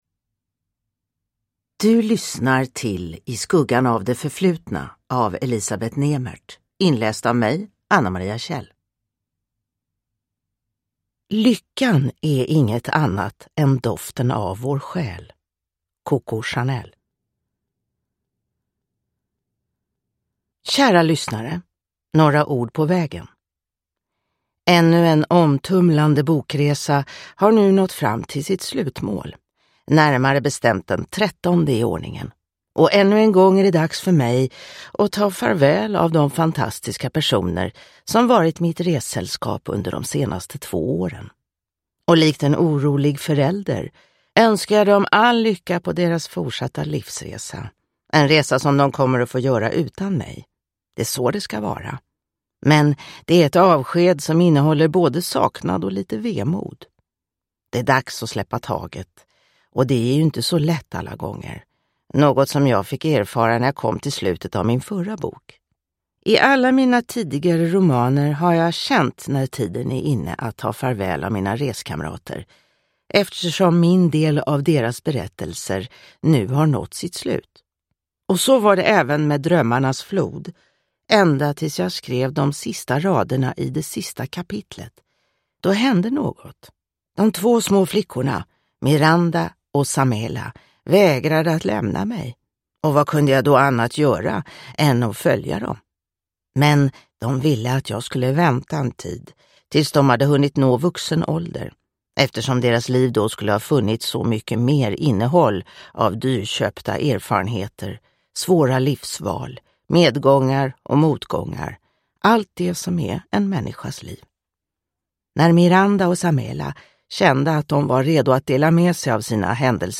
I skuggan av det förflutna – Ljudbok